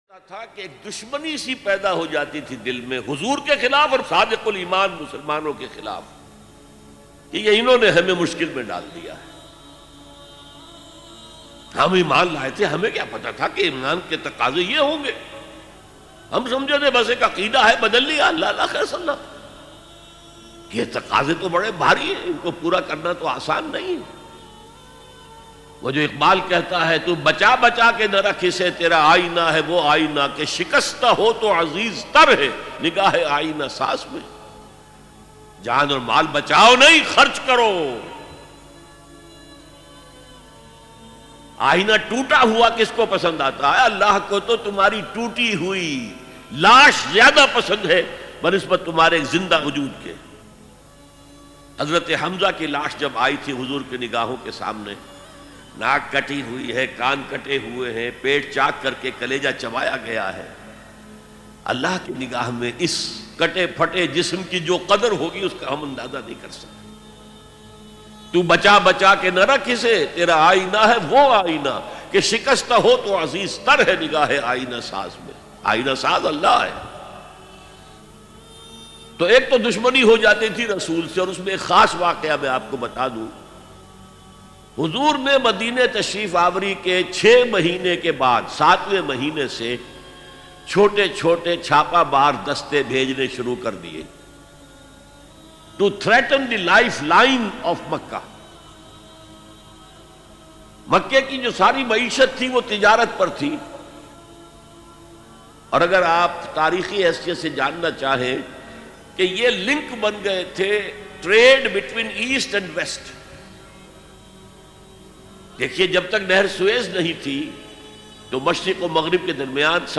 Dr Israr Ahmed Very Emotional Bayan MP3 Download